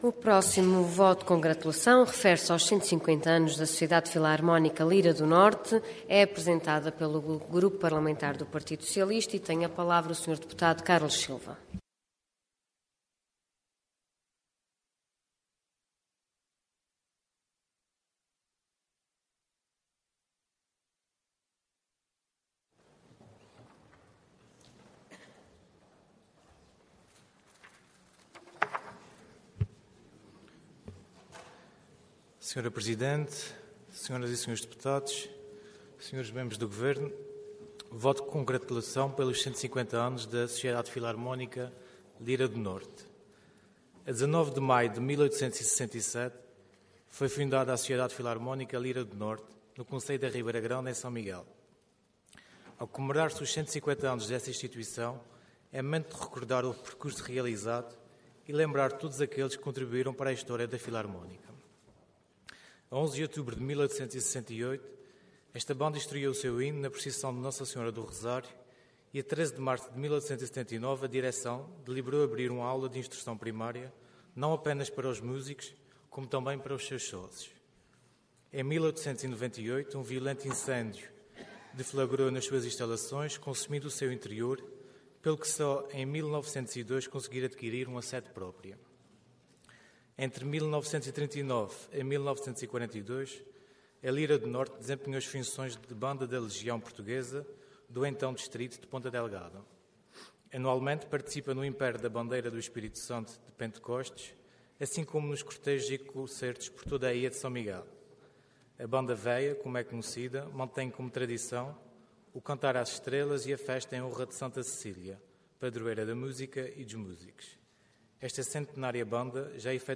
Intervenção Voto de Congratulação Orador Carlos Silva Cargo Deputado Entidade PS